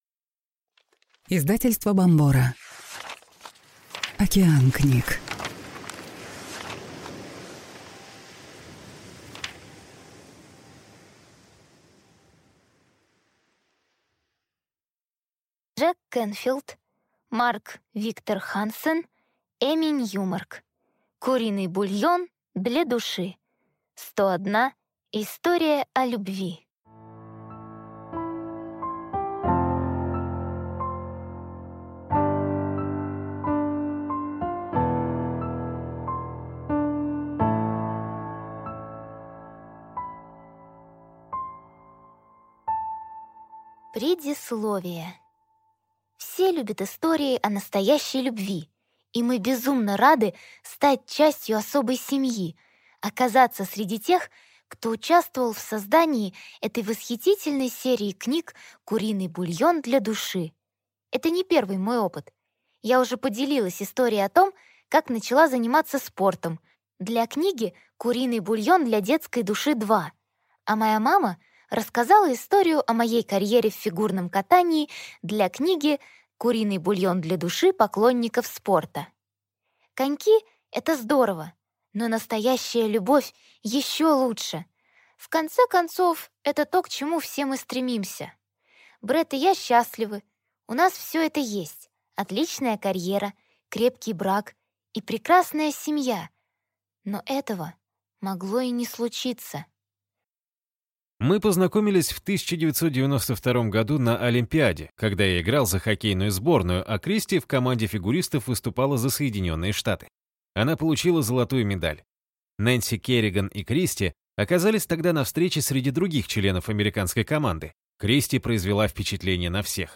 Аудиокнига Куриный бульон для души. 101 история о любви | Библиотека аудиокниг